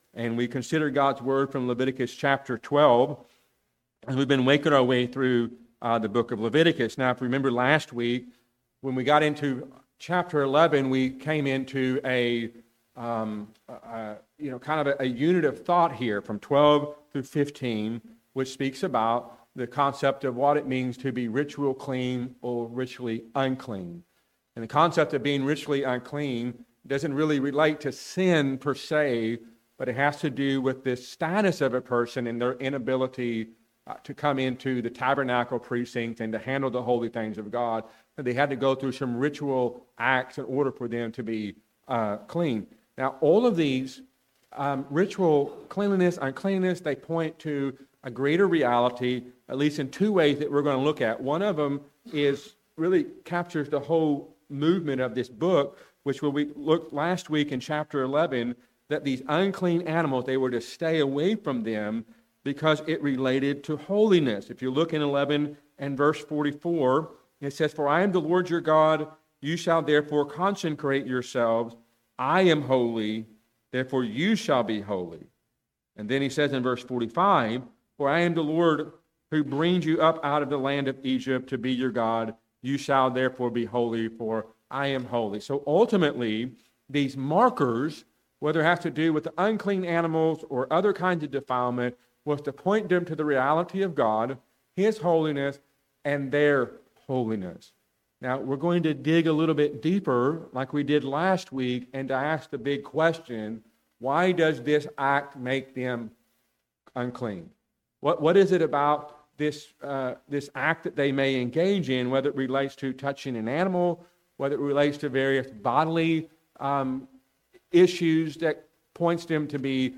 A sermon from Leviticus 12.